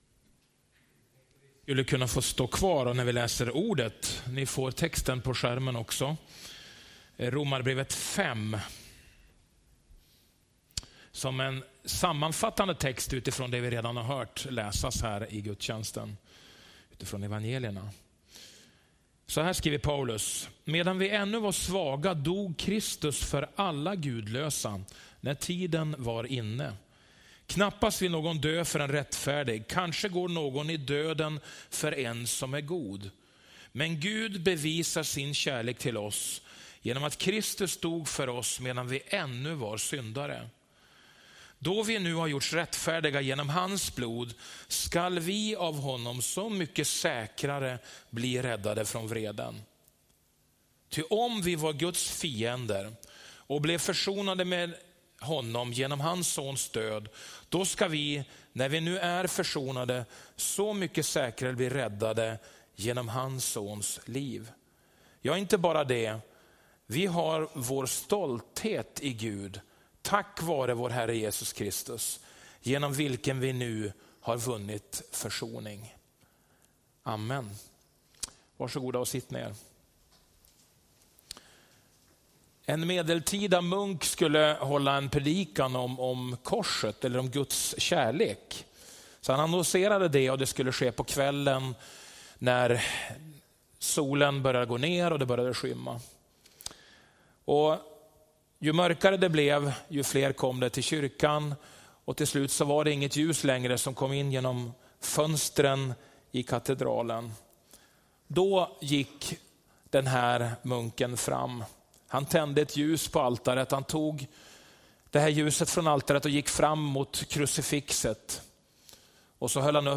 Predikan långfredagen, 30 mars 2018